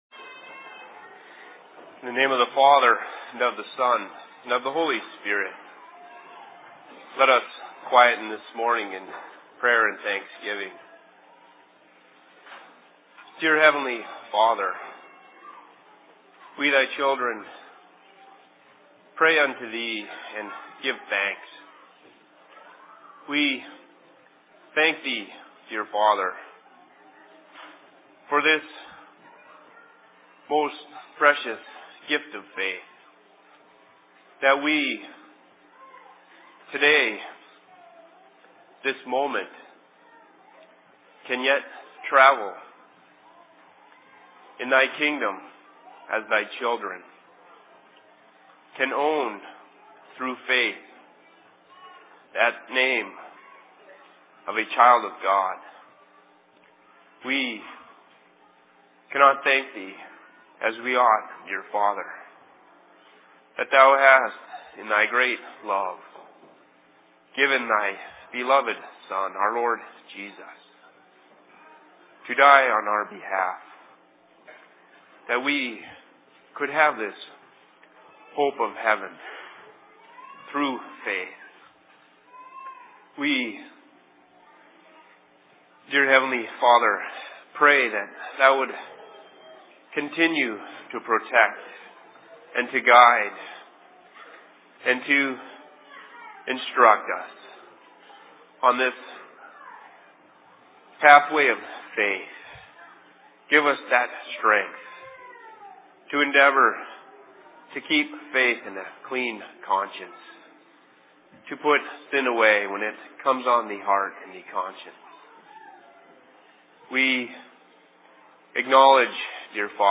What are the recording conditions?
Location: LLC Seattle